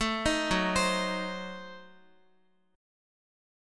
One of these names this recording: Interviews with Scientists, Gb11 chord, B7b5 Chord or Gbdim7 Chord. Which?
Gbdim7 Chord